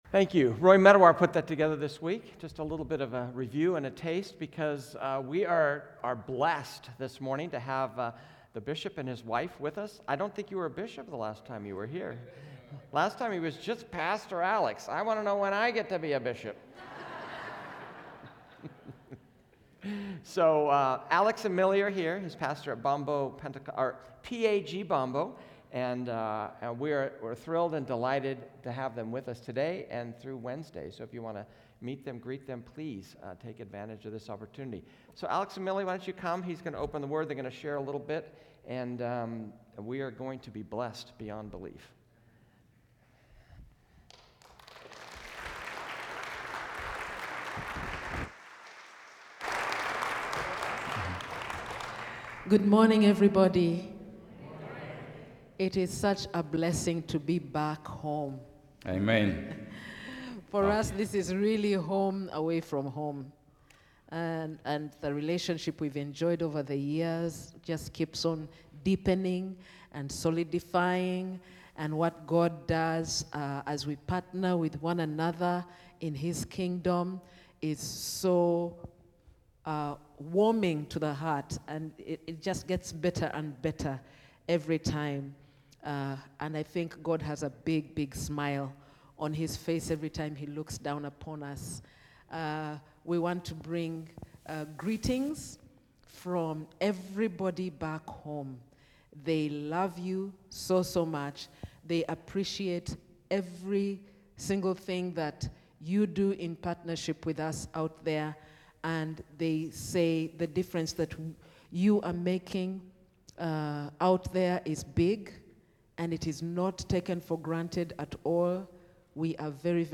A message from the series "Timeless."